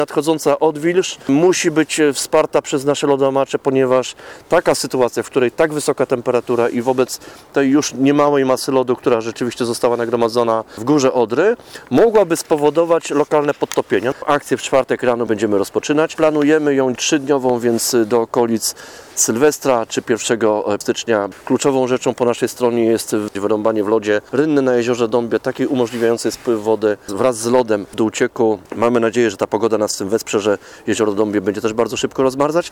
– Mówi Marek Duklanowski – Dyrektor Regionalnego Zarządu Wód Polskich.